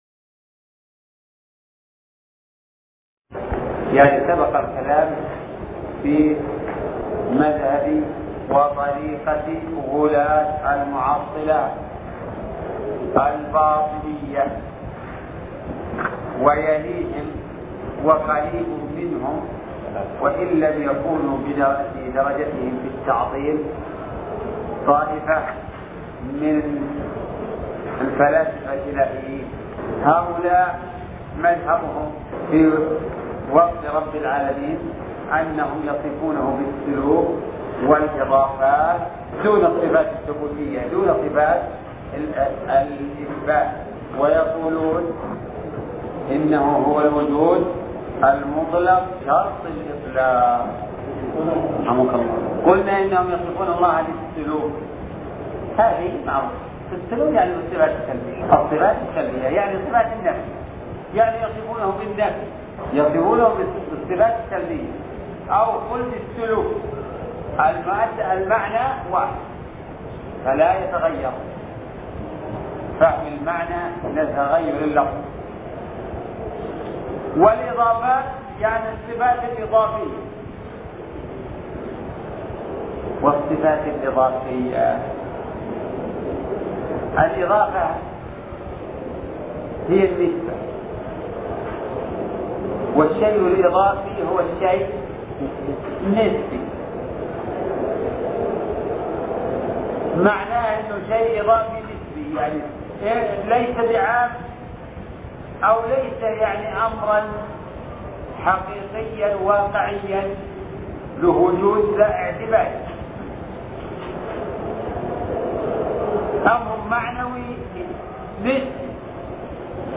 الدرس (6) شرح التدمرية